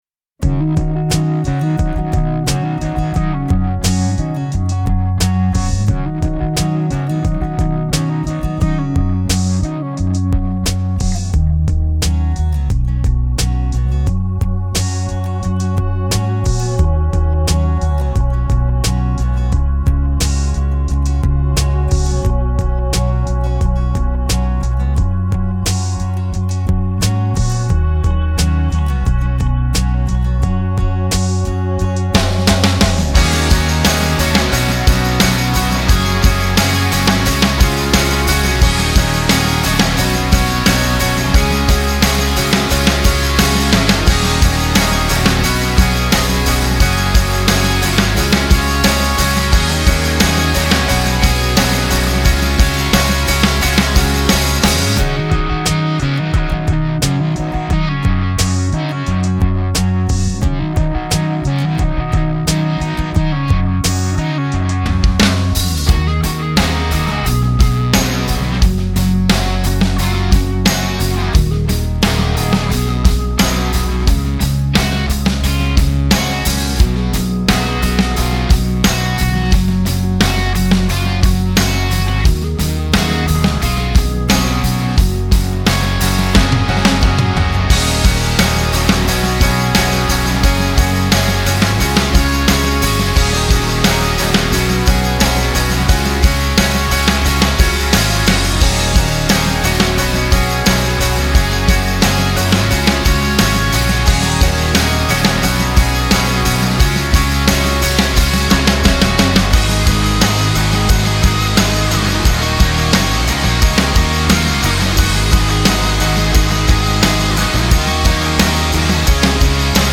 Key F#/Gb
Instrumental Track